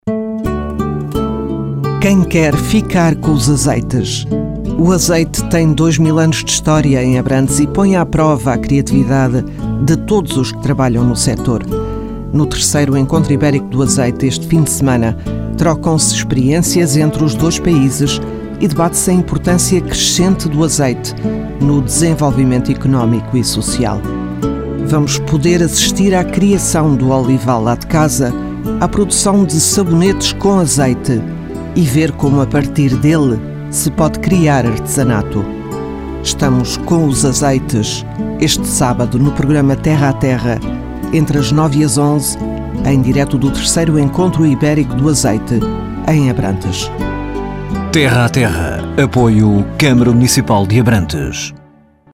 Download this file (Terra a Terra de Abrantes.mp3)Ouvir Spot Publicitário[ ]2037 kB